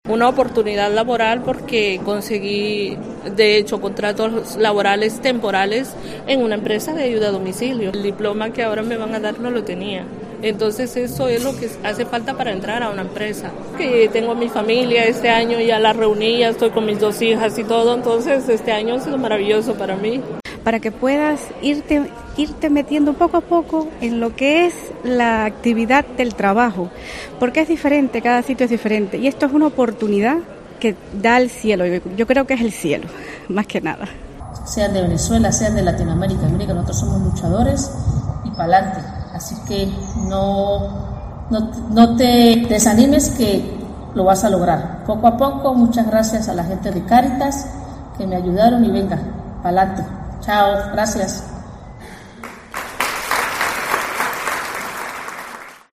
Varias alumnas cuentan la oportunidad que ha supuesto para ellas las actividades formativas de Cáritas